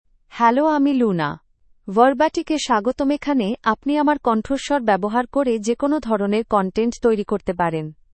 Luna — Female Bengali AI voice
Luna is a female AI voice for Bengali (India).
Voice sample
Listen to Luna's female Bengali voice.
Female
Luna delivers clear pronunciation with authentic India Bengali intonation, making your content sound professionally produced.